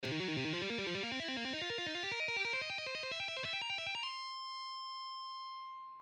Lesson 4: Cm pentatonic Blues Scale
Original Speed:
Exercise-4-Cm-Pentatonic-blues-scale.mp3